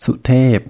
Leider nein, langes "e":